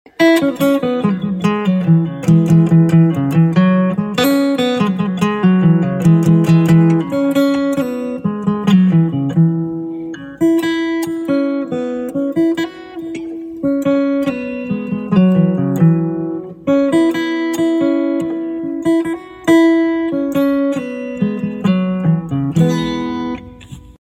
Grass cutting asmr video sound effects free download